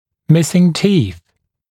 [‘mɪsɪŋ tiːθ][‘мисин ти:с]отсутствующие зубы, отсутствие зубов